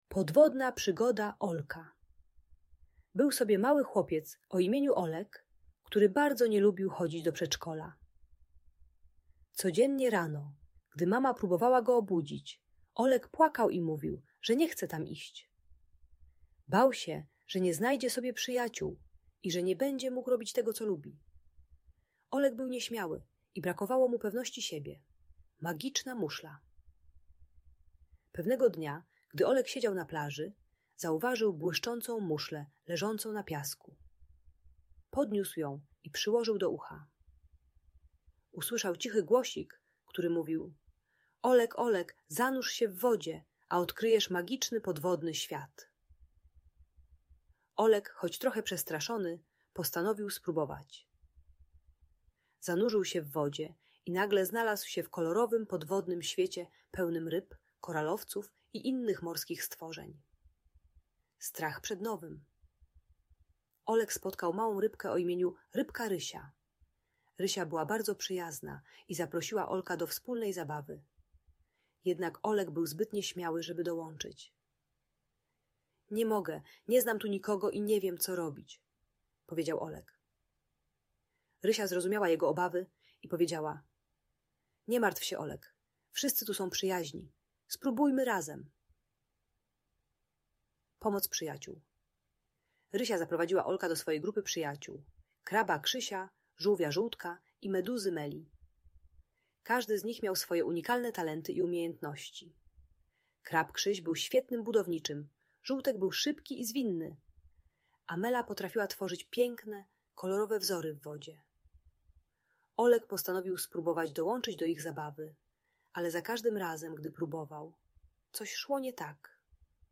Podwodna Przygoda Olka - Magiczna Muszla | Bajkowa Story - Audiobajka